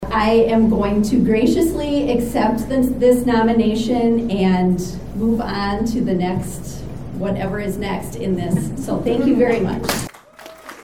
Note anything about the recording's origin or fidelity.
At their 2022 State Convention over the weekend (July 8-9, 2022) in Fort Pierre, the South Dakota Democratic Party nominated candidates for constitutional offices, adopted its platform, adopted an amendment to the constitution and passed resolutions.